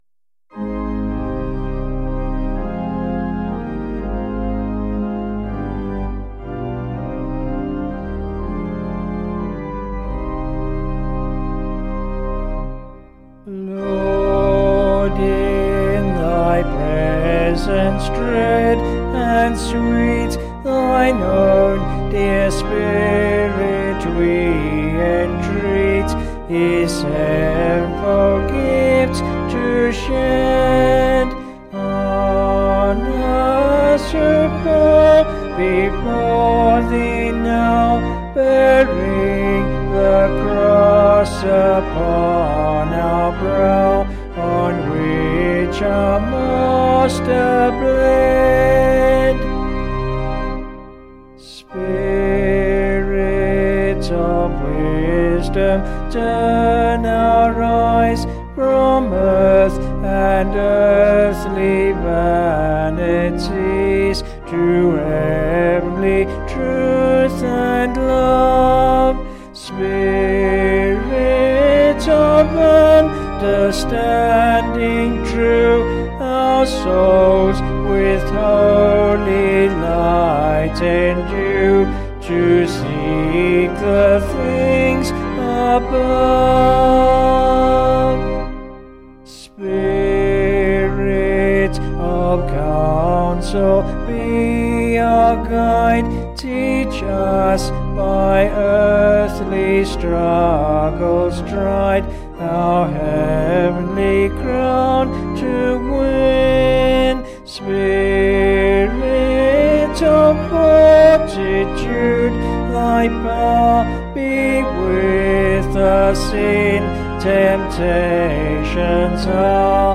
Vocals and Organ   266.4kb Sung Lyrics